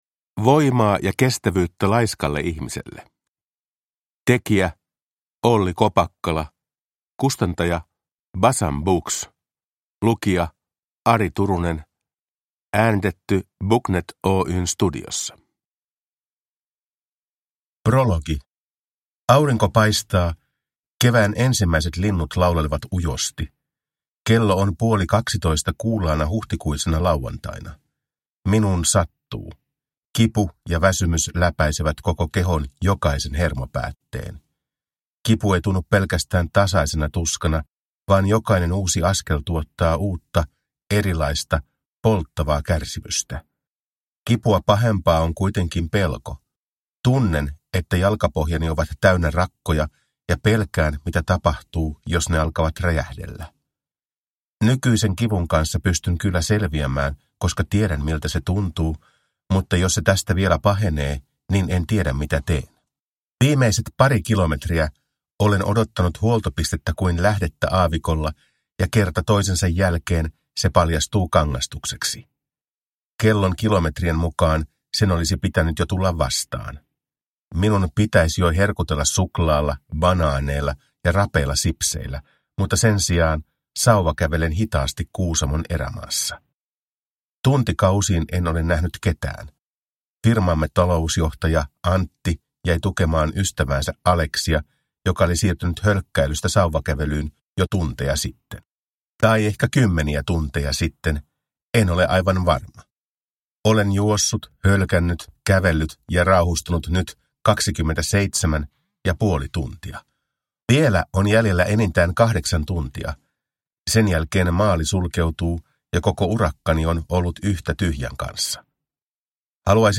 Voimaa ja kestävyyttä laiskalle ihmiselle – Ljudbok